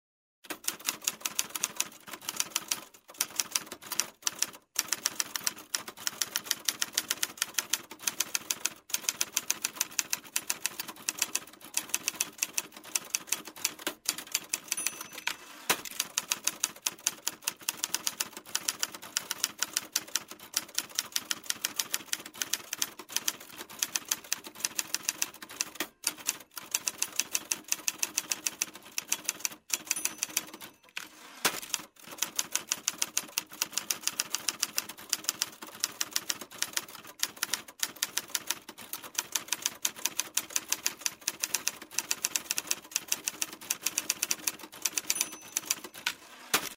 Звук печатной машинки во время набора текста